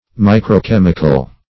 Search Result for " microchemical" : The Collaborative International Dictionary of English v.0.48: microchemical \mi`cro*chem"ic*al\, micro-chemical \mi`cro-chem"ic*al\, a. Of or pertaining to microchemistry; as, a micro-chemical test.